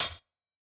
UHH_ElectroHatA_Hit-25.wav